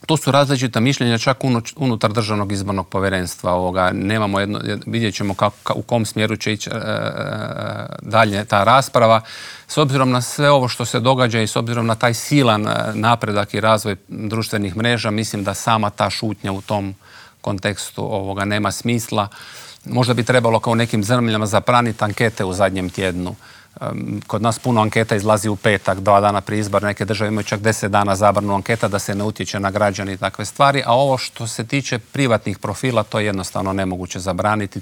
O nadolazećim izborima, pravilima glasovanja, ali i o izbornoj šutnji razgovarali smo u Intervjuu tjedna Media servisa s članom Državnog izbornog povjerenstva Slavenom Hojskim.